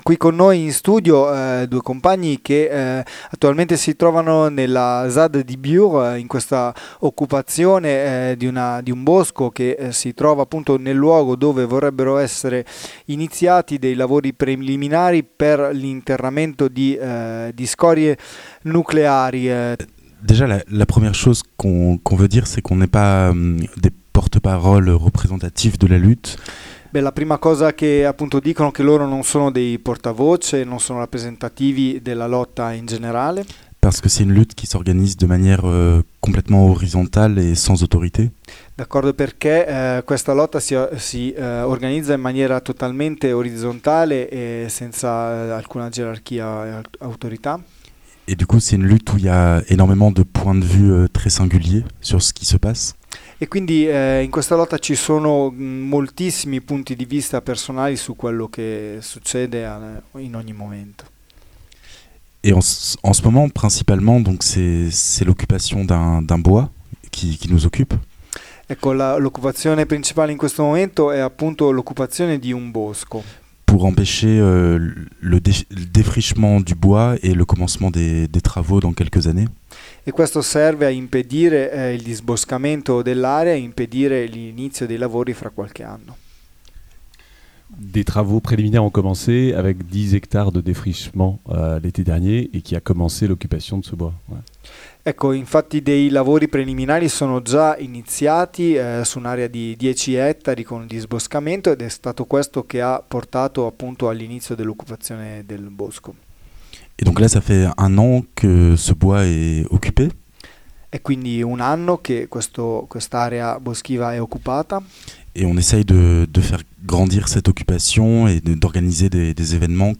Collegamento telefonico con uno dei solidali presenti ieri allo sgombero.